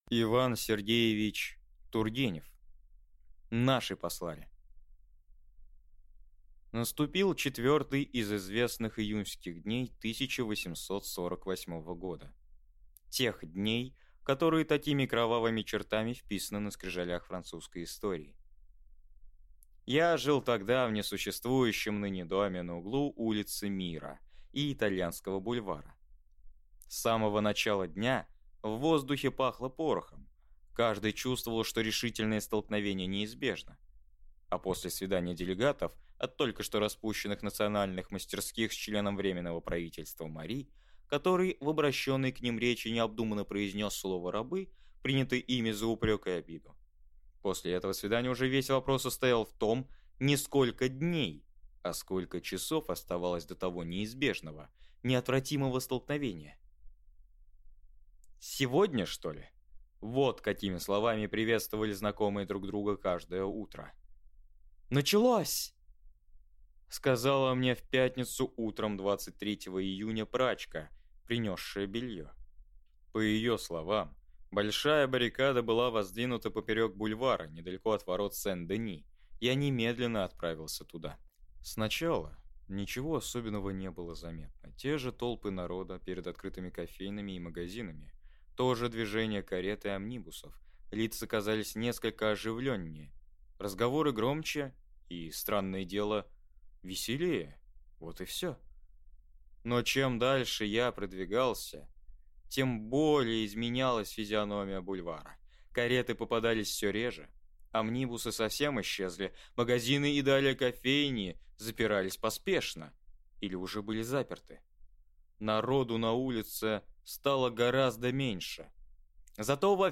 Aудиокнига Наши послали!